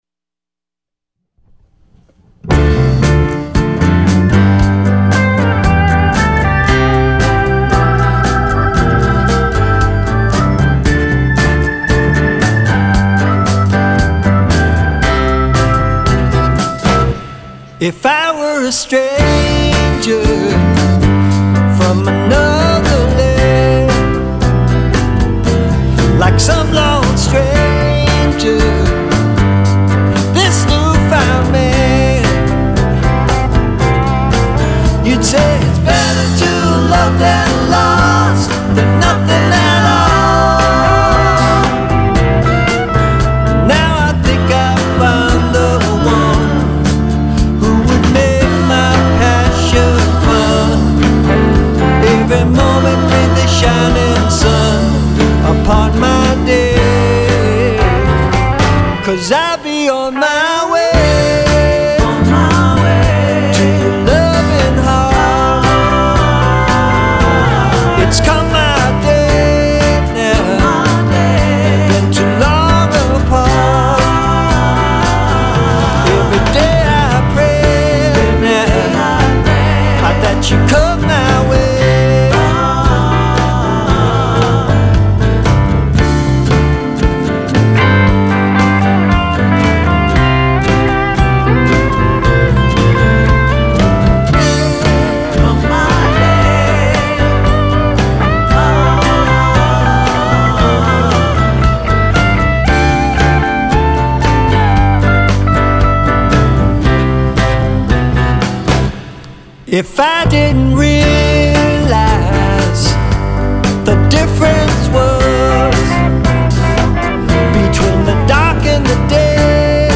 Hammond B3 organ